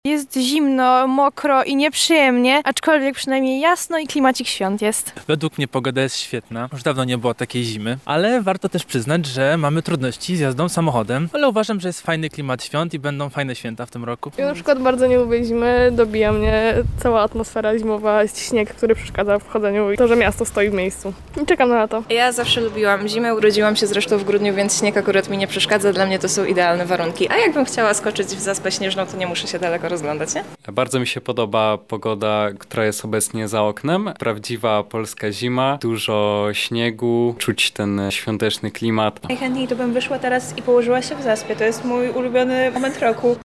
[SONDA] Lublinianie, a śnieg
Zapytaliśmy mieszkańców Lublina, jak oceniają dzisiejszą pogodę:
sonda